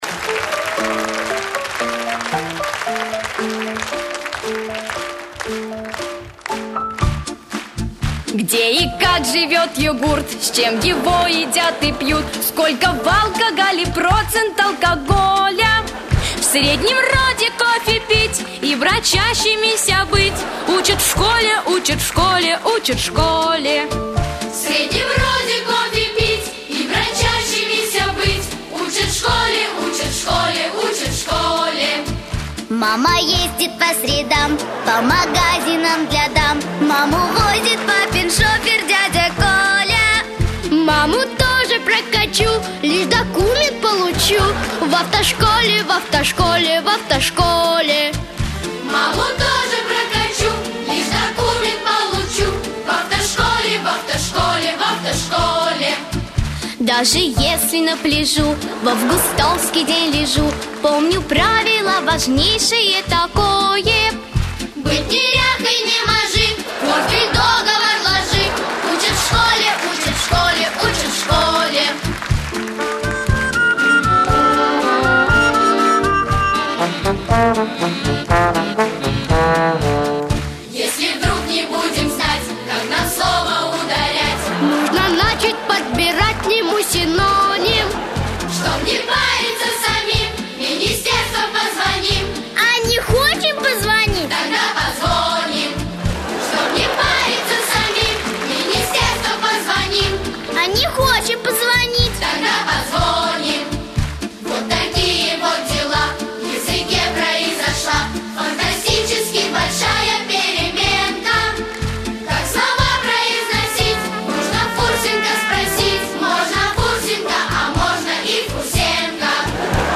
ПЕСЕНКА:)